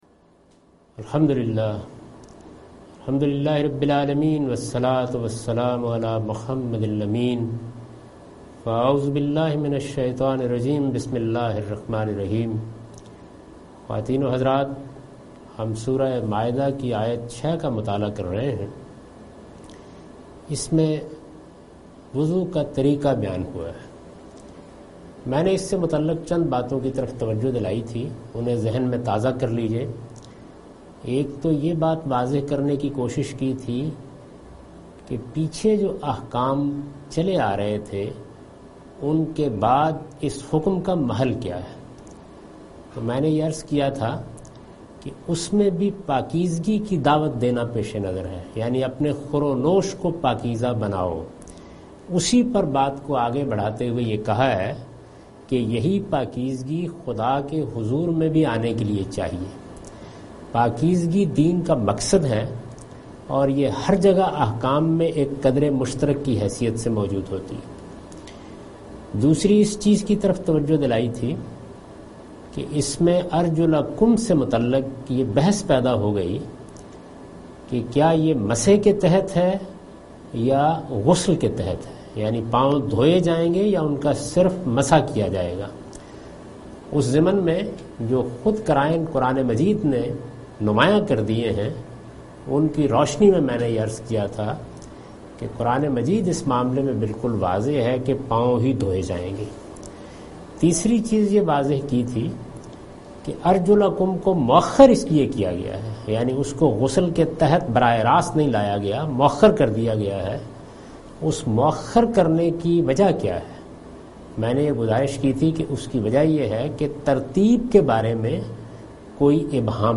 Surah Al-Maidah - A lecture of Tafseer-ul-Quran, Al-Bayan by Javed Ahmad Ghamidi.